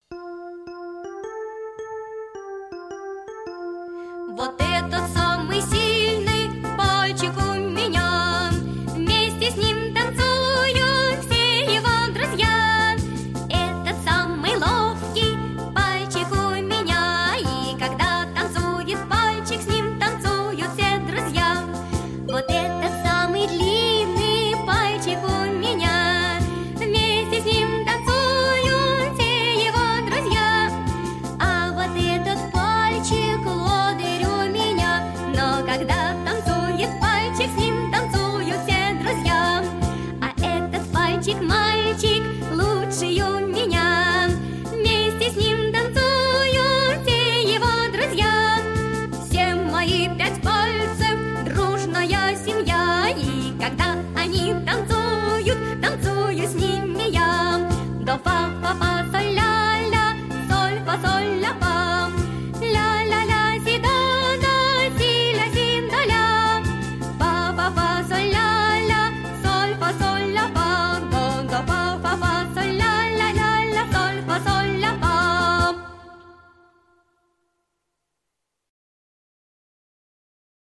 Танцуют пальчики - песенка с движениями - слушать онлайн